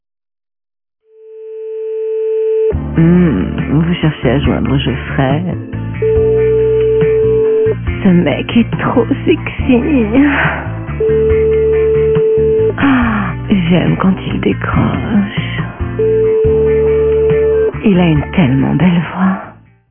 - Personnalisez votre tonalité d’attente et faites patienter vos correspondants autrement ! -